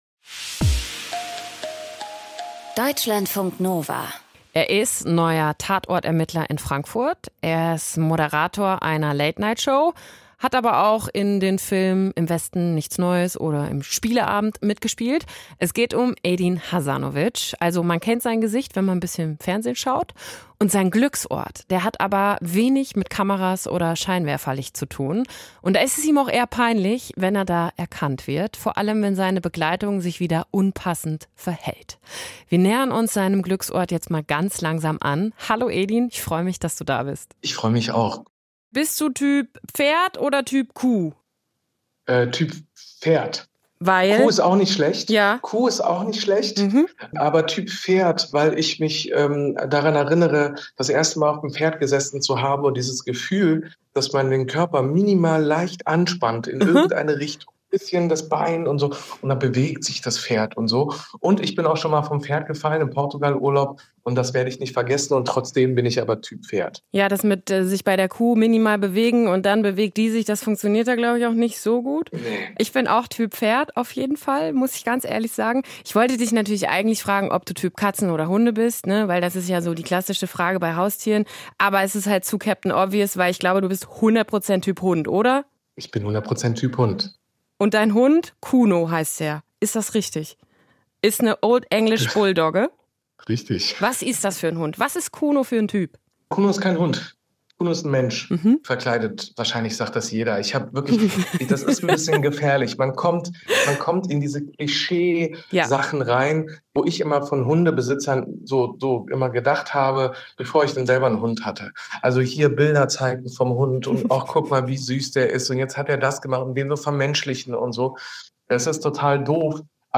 dass ich mich beim Schauspielen öffnen kann“ 32:13 Minuten Zu Gast in unserer Sendung: die Schauspielerin Barbara Auer © imago